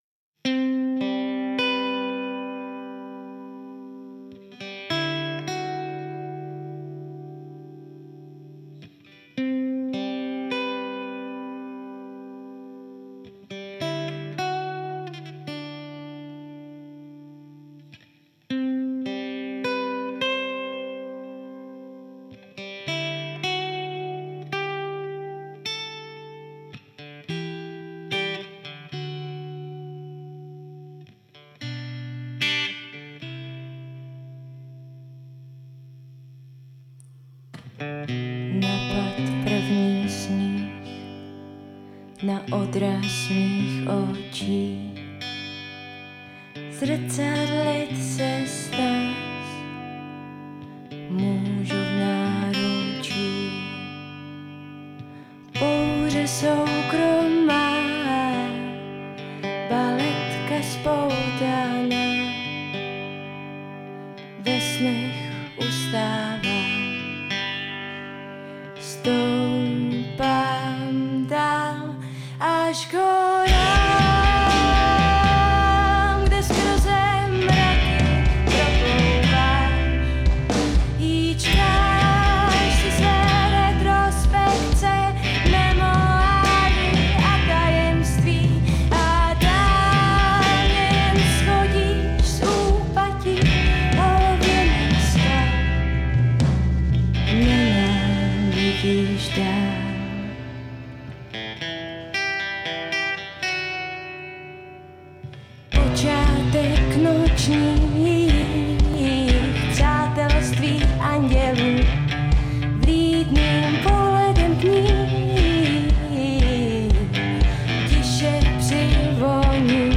baskytaru
bubeník